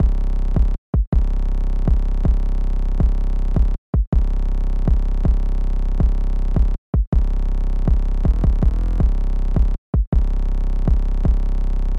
Tag: 160 bpm Trap Loops Drum Loops 2.02 MB wav Key : C